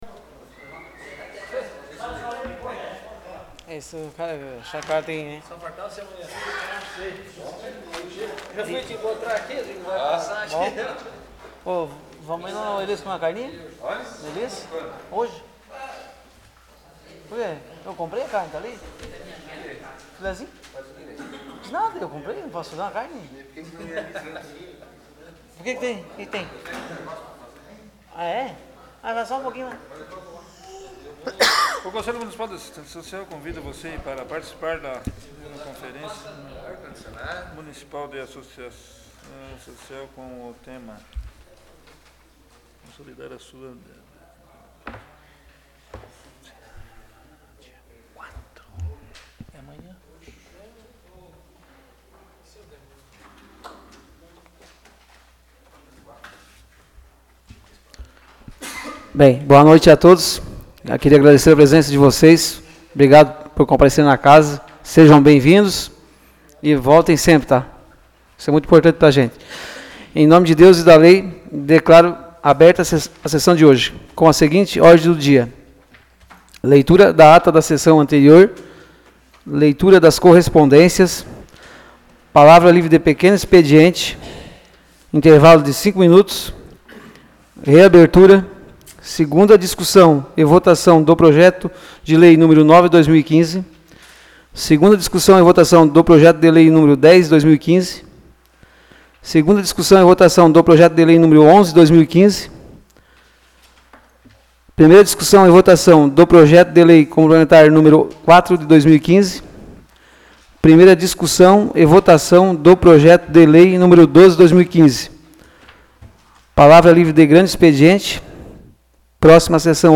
Áudio da Sessão Ordinária de 03 de agosto de 2015